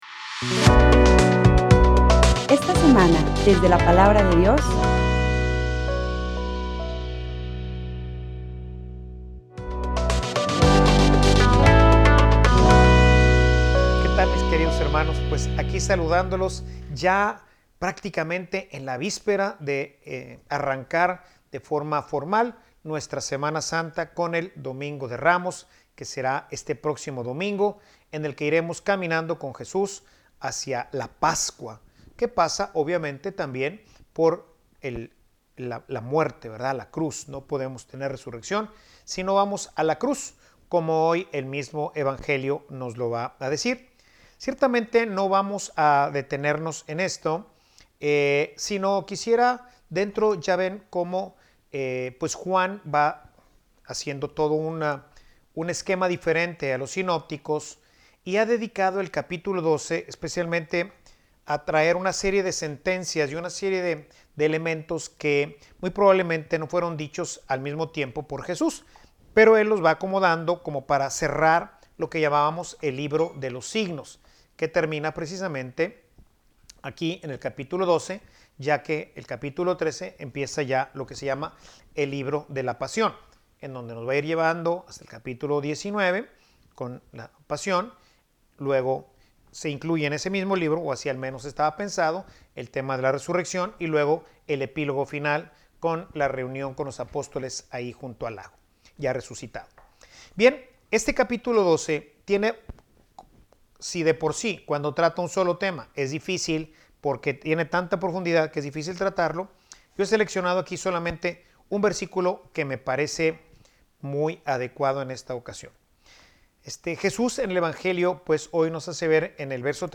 Homilia_Tu_sabes_a_donde_vas.mp3